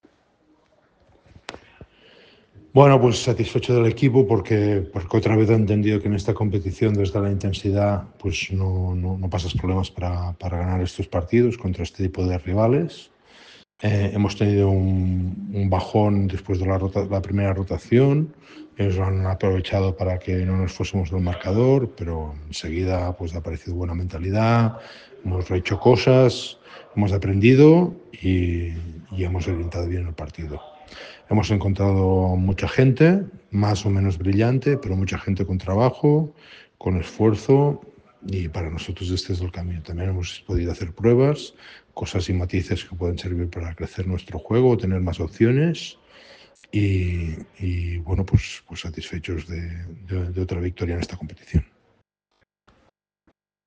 Declaraciones de Jaume Ponsarnau